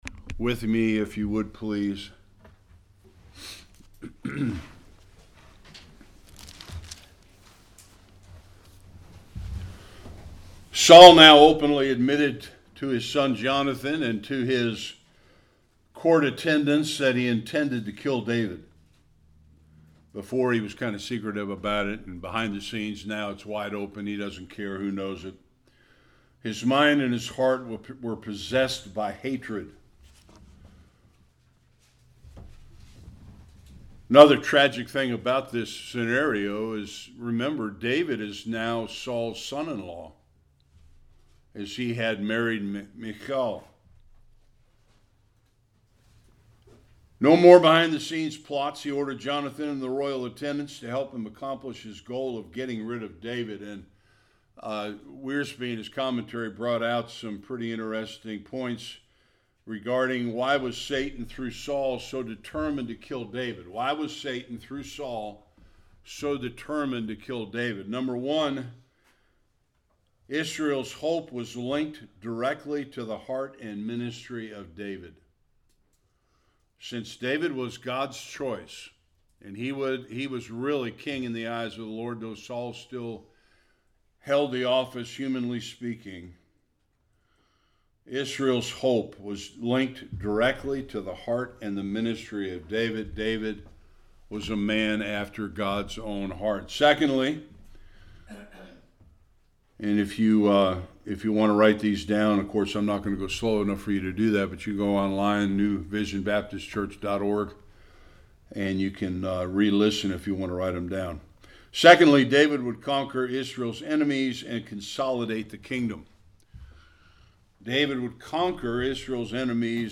1-24 Service Type: Sunday School King Saul’s hatred for David continues to grow.